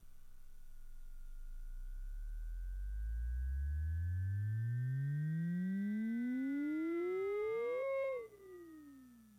描述：通过Modular Sample从模拟合成器采样的单音。
Tag: MIDI-速度-64 F6 MIDI音符-90 Sequntial-MAX 合成器 单票据 多重采样